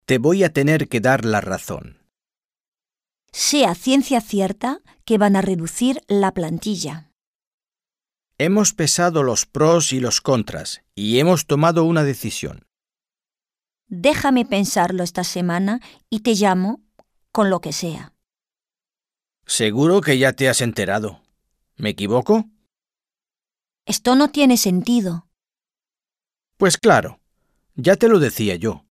Un peu de conversation - L'opinion, la conviction